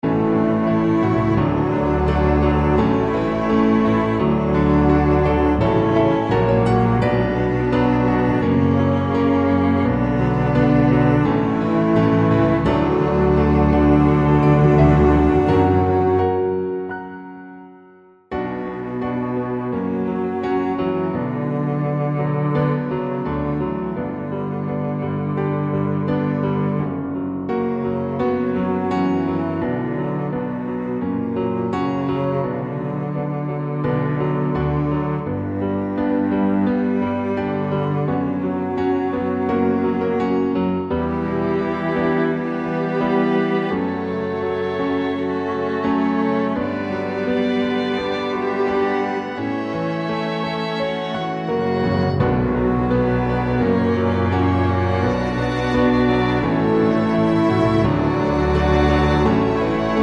Down 2 Semitones